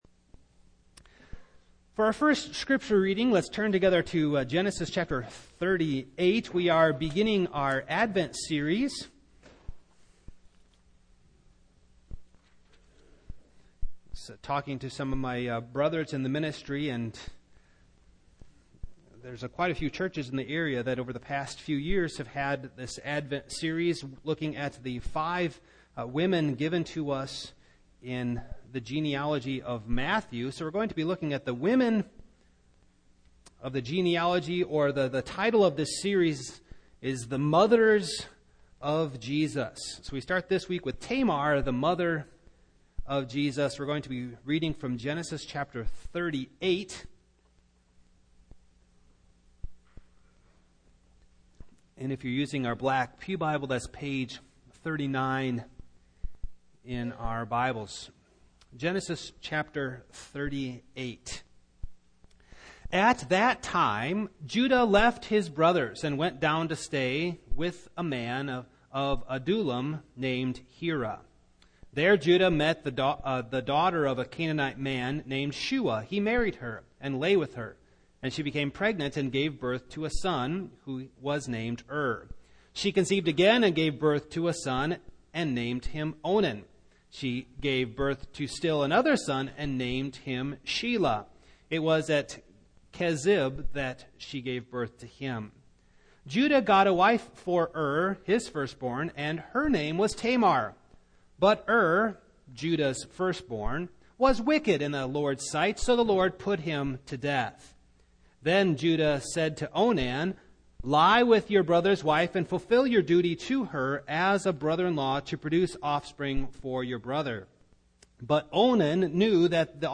Series: Single Sermons Tamar
Service Type: Morning